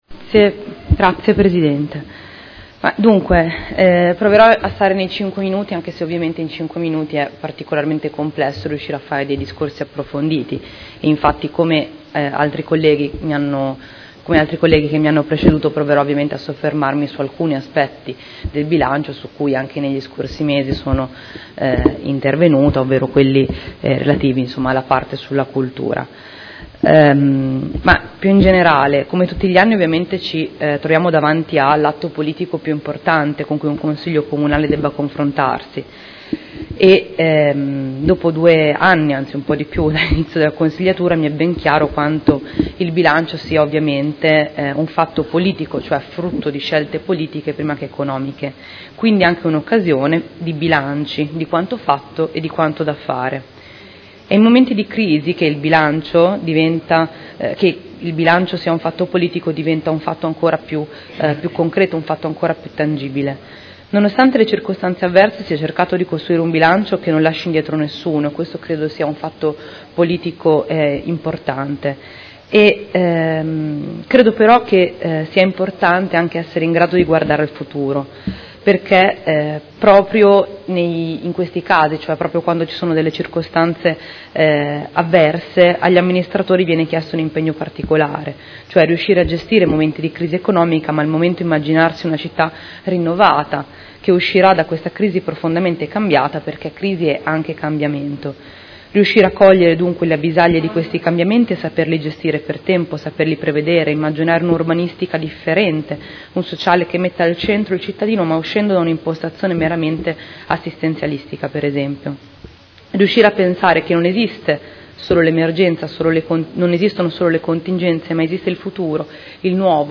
Seduta del 26 gennaio. Bilancio preventivo: Dibattito